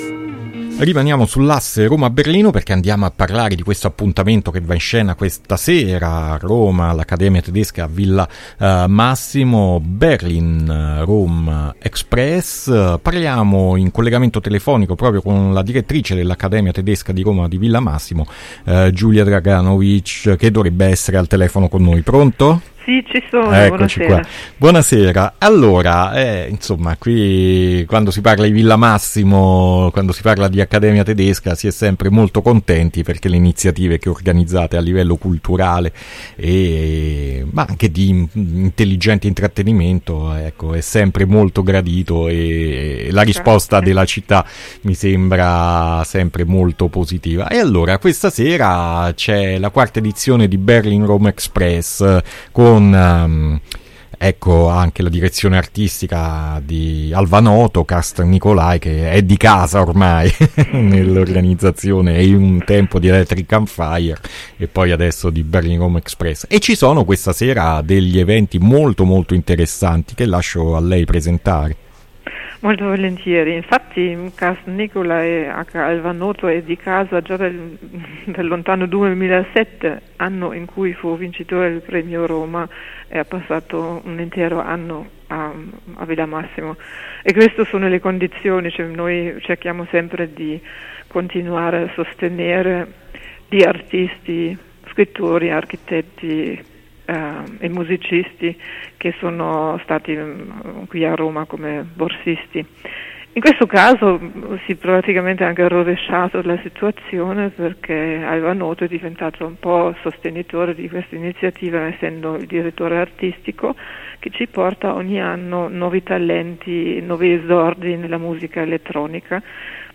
intervista-berlin-romexpress-2021.mp3